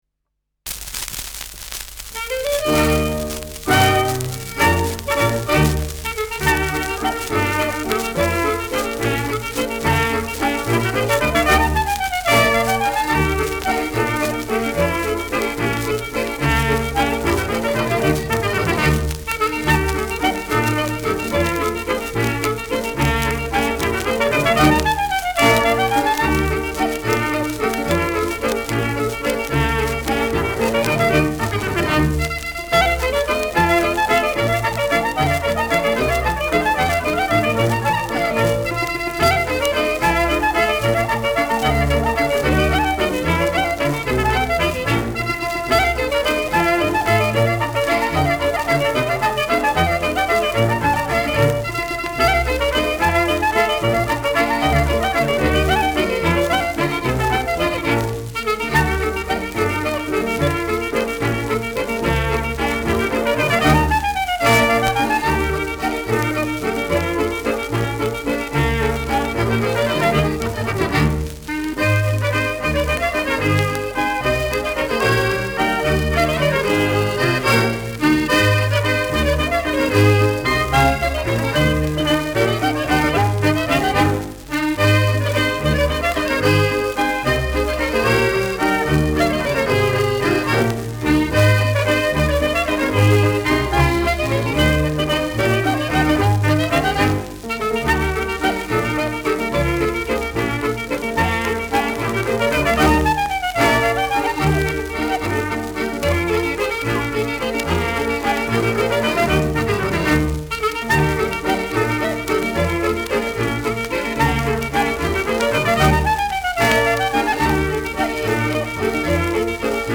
Schellackplatte
leichtes Rauschen : Knistern
Ländlerkapelle* FVS-00018